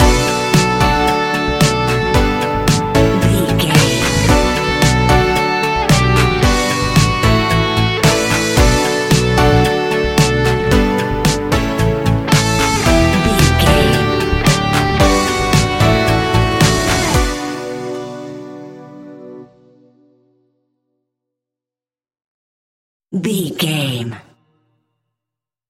Ionian/Major
B♭
ambient
new age
downtempo
pads